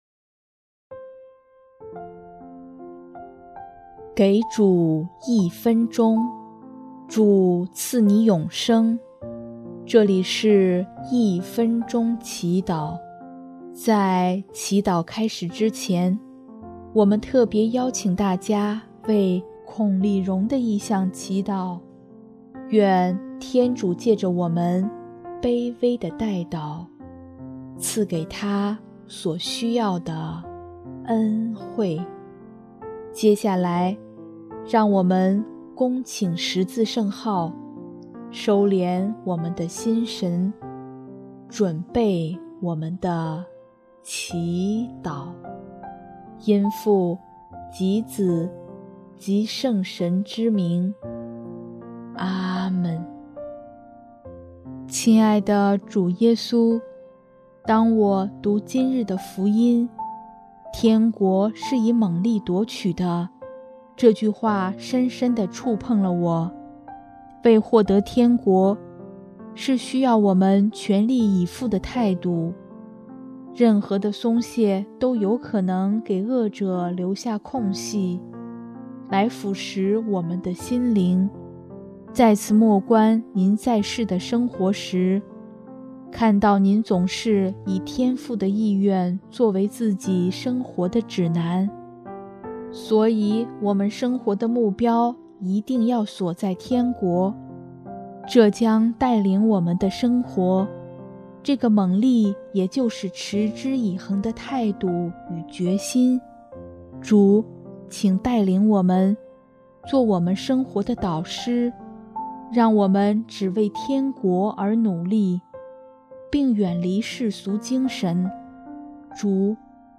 【一分钟祈祷】|12月11日 目标的重要性